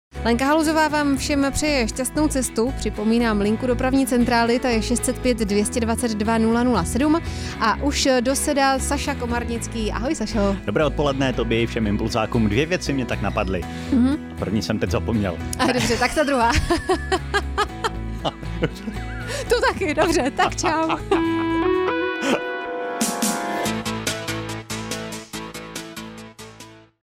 Rádio Impuls – předávka 😉